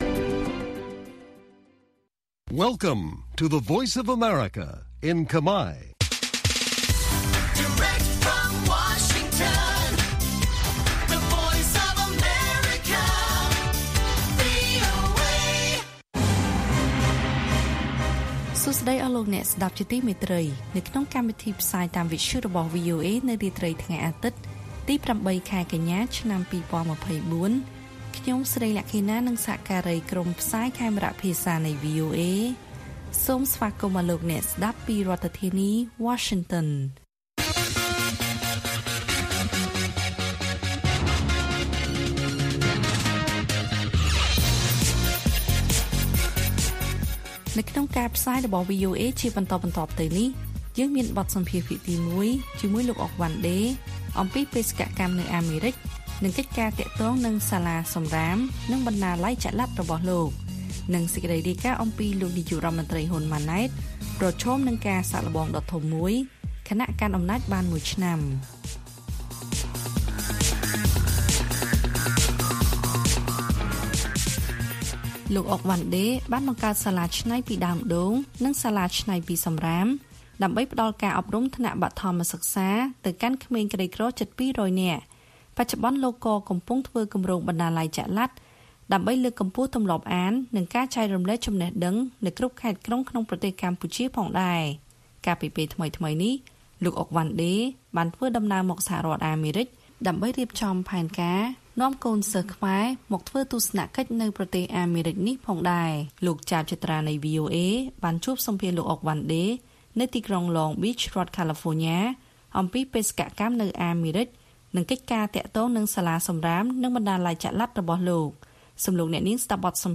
ព័ត៌មានពេលរាត្រី ៨ កញ្ញា៖ បទសម្ភាសន៍ភាគទី១